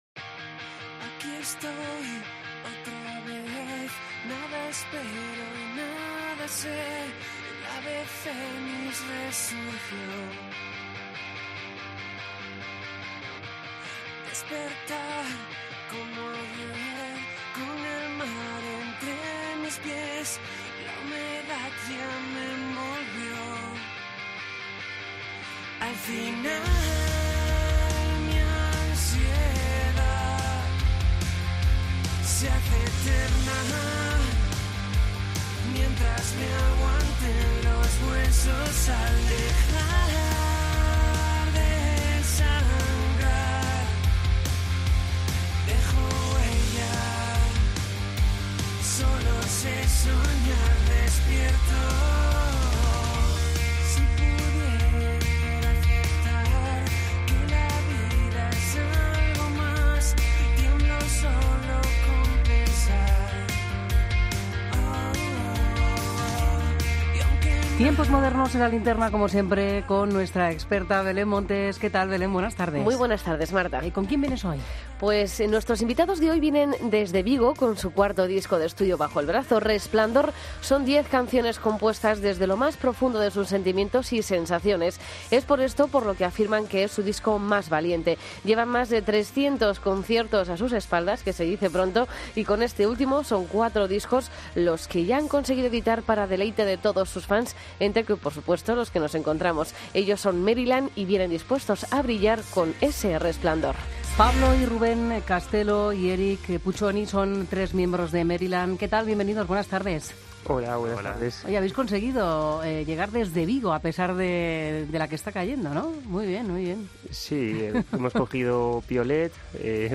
Entrevista a Maryland en La Linterna